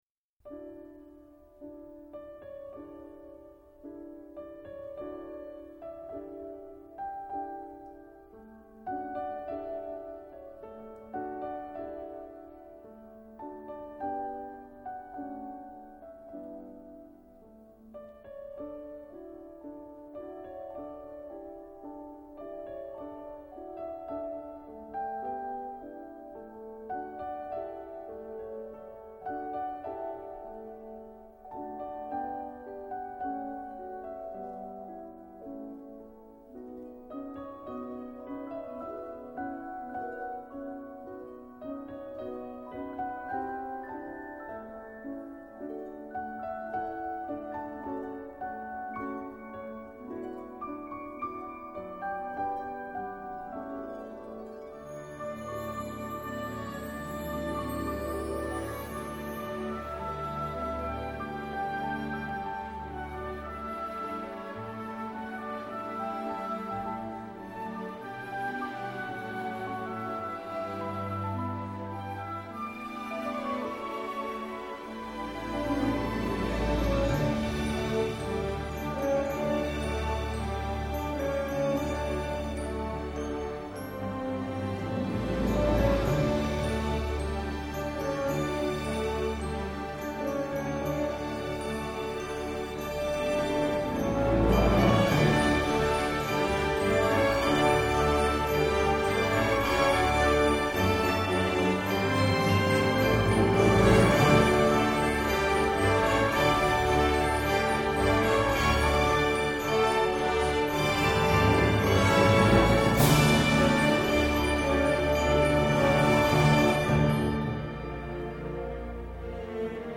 These two themes embody triumph and majesty.